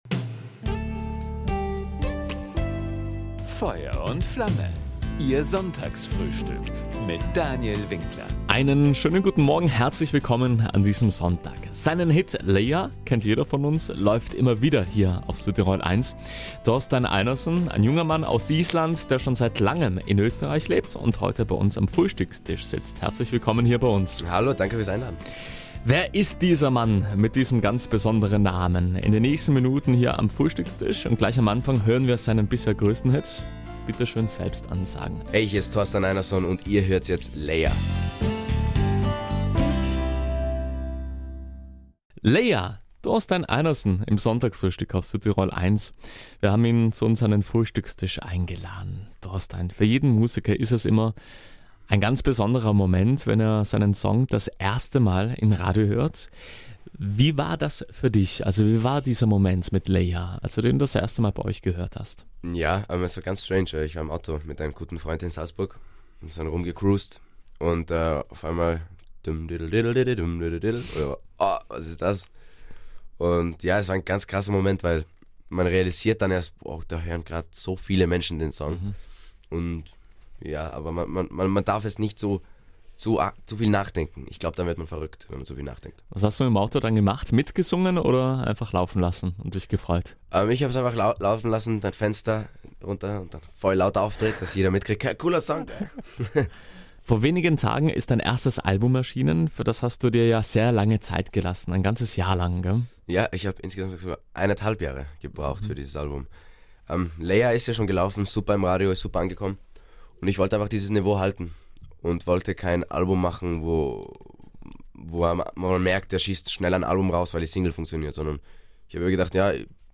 Beim Sonntagsfrühstück auf Südtirol 1 (ab 10 Uhr) gewährte er nicht nur Einblick in das außergewöhnliche Leben als Sänger, Songwriter und Popstar, sondern auch einen Blick hinter die Kulissen.